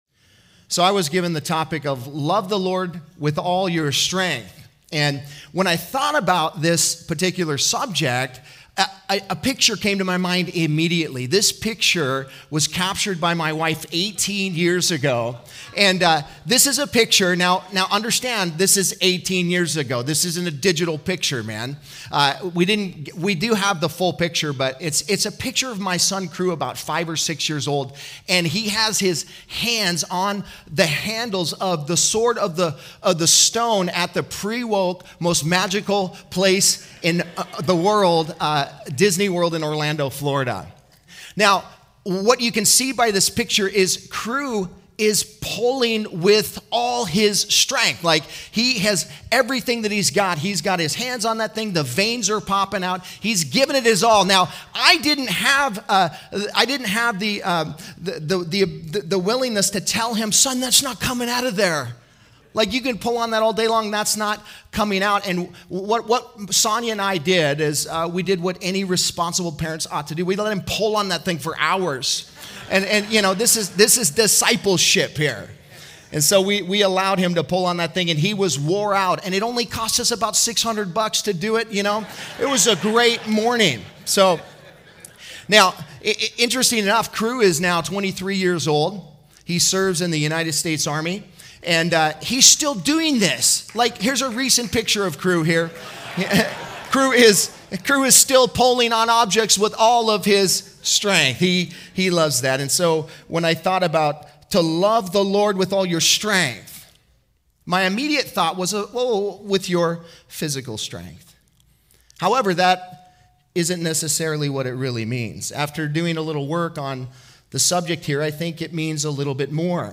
Home » Sermons » Loving God with All Your Strength
2023 DSPC Conference: Pastors & Leaders